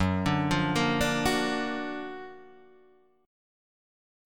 Gb+M7 chord